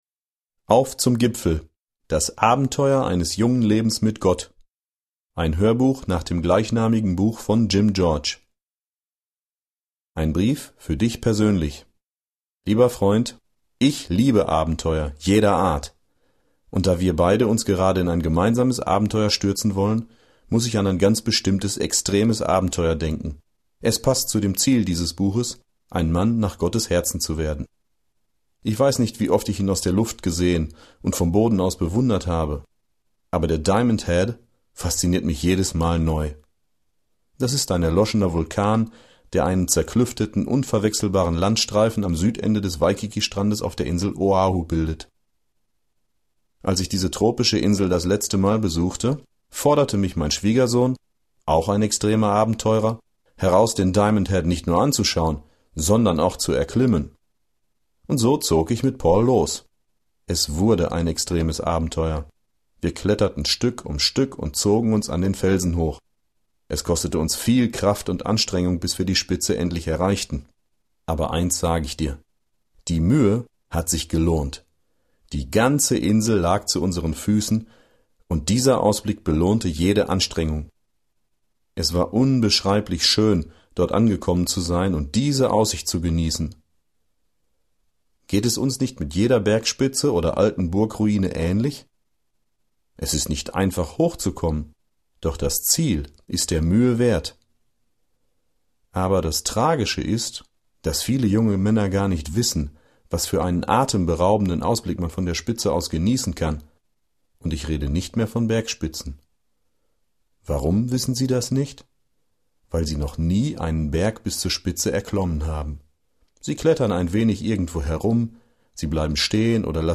Auf zum Gipfel (MP3-Hörbuch)